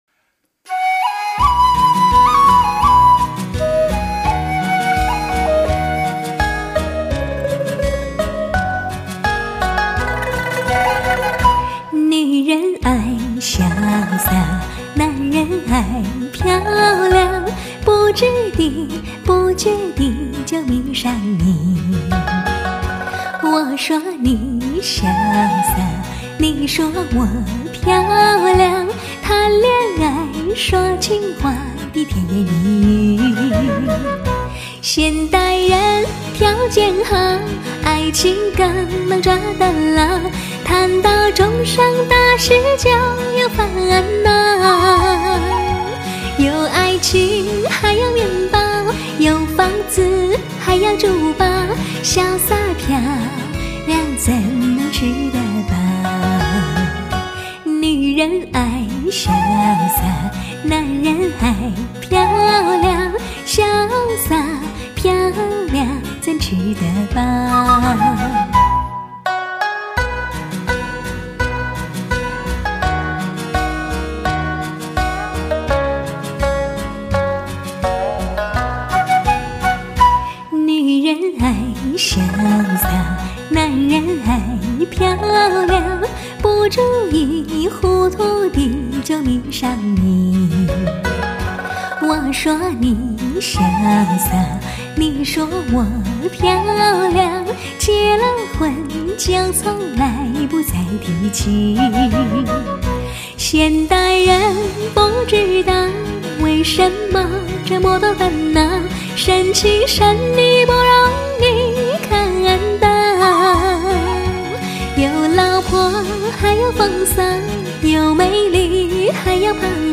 唱片类型：华语流行